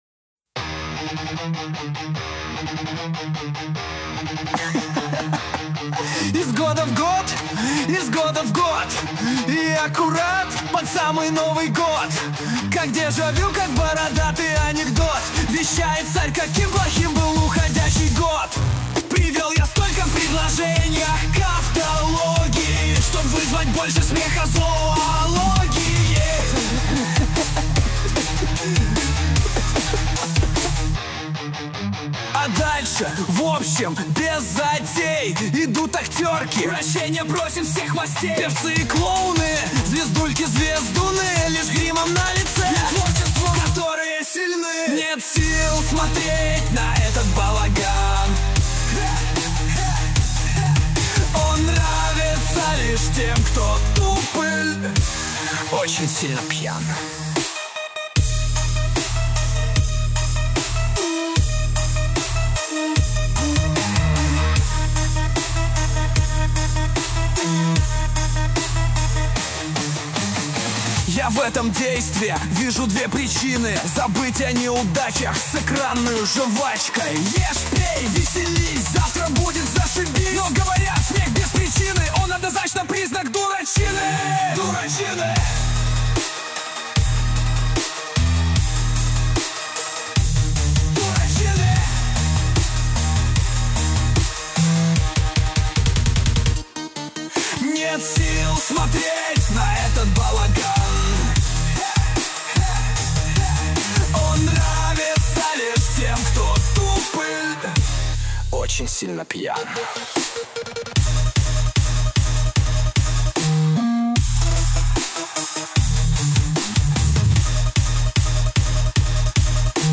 mp3,2989k] Рок